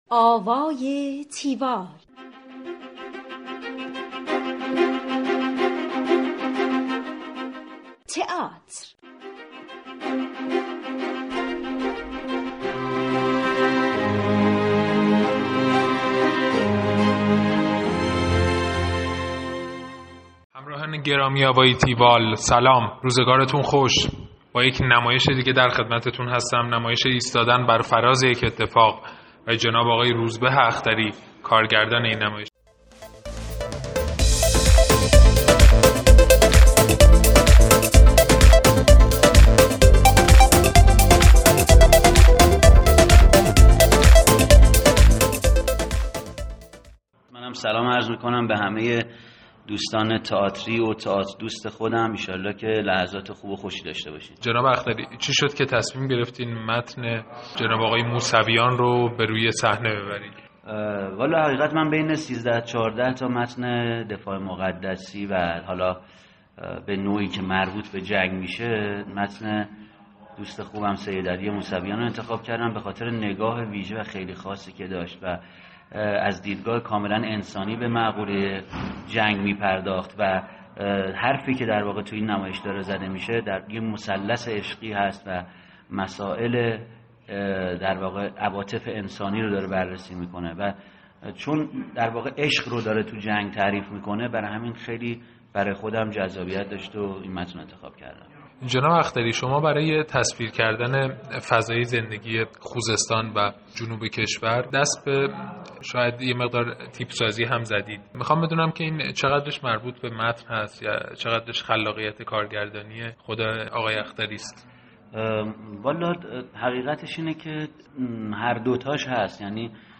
گفتگوی تیوال